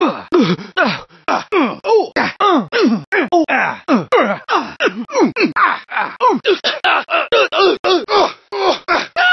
gta-san-andreas-pedestrian-voices-coughing-pain-male-audiotrimmer_TMaB4My.mp3